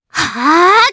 Viska-Vox_Casting1_jp.wav